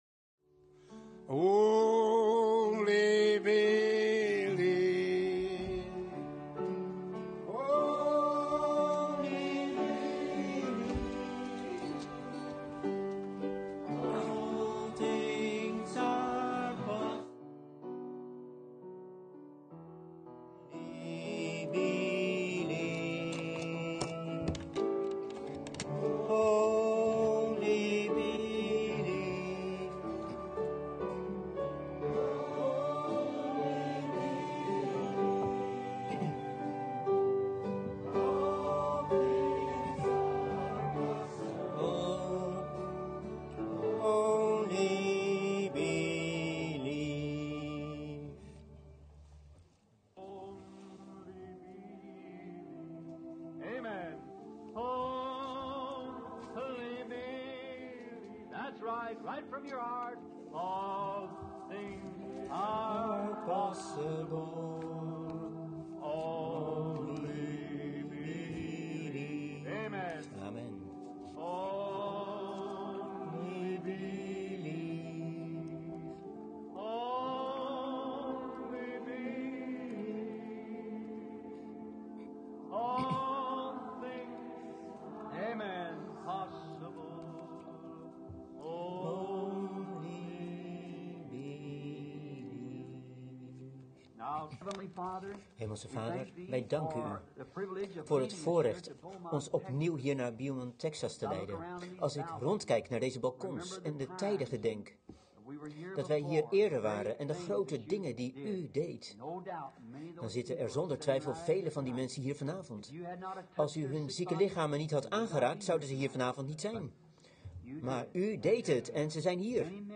Prediking
Locatie Municipal auditorium Beaumont , TX